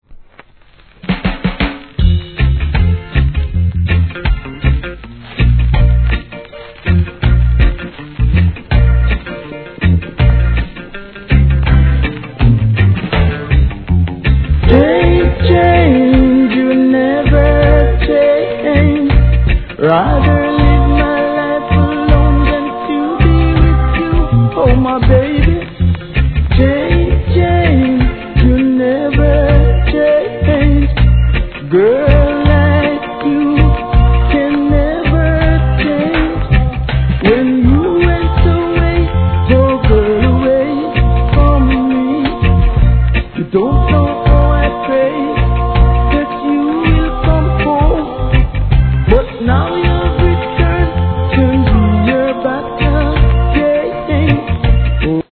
REGGAE
味のあるヴォーカルにコーラスが見事にハモるEARLY REGGAE!!